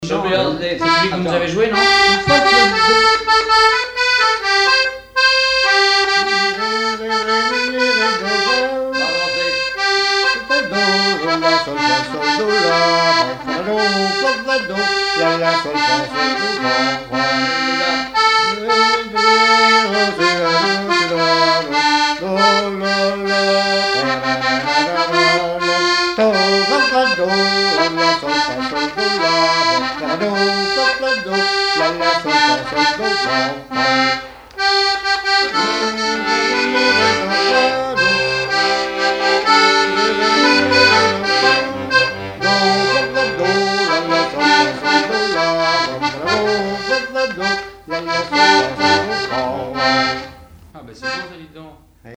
instrumental
danse : quadrille : chaîne anglaise
Pièce musicale inédite